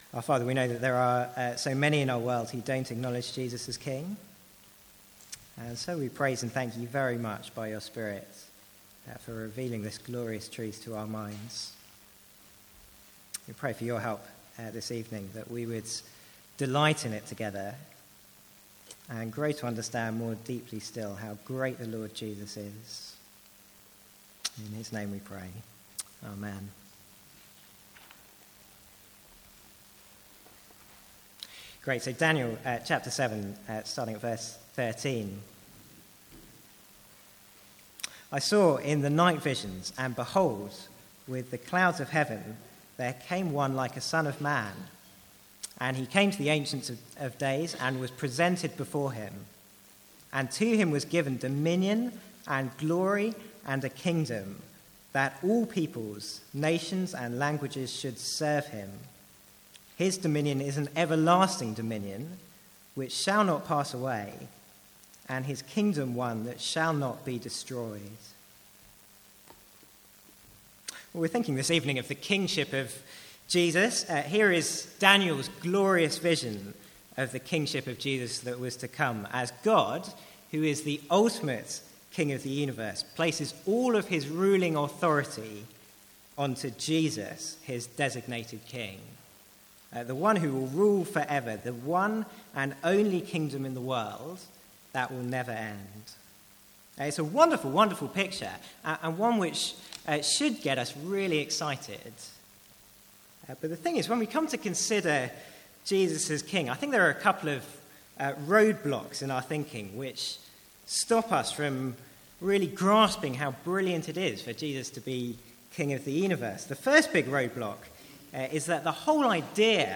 Sermons | St Andrews Free Church
From the Sunday evening series 'Jesus as Prophet, Priest and King'.